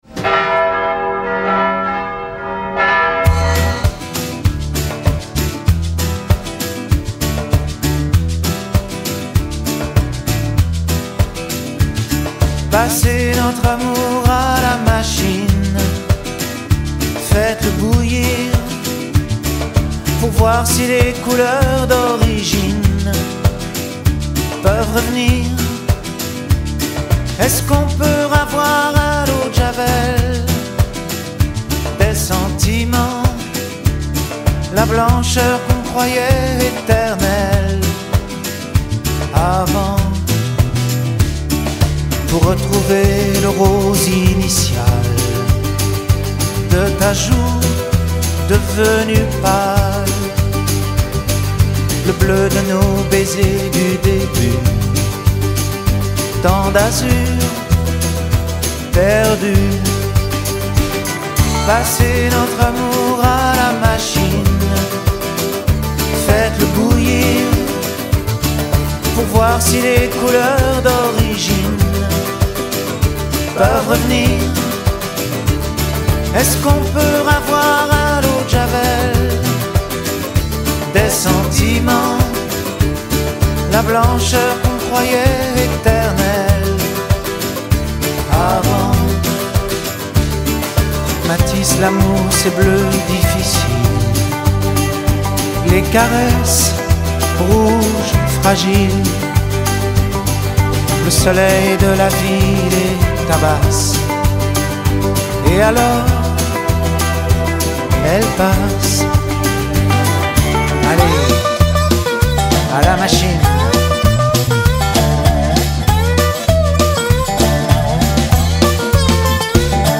tonalité SOL majeur